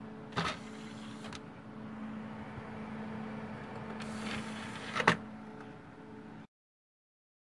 技术 " 前置式驱动器
Tag: 马达 电脑 MAC CD 删除 DVD 卸载 弹出 DVD驱动器 装载 插入 负载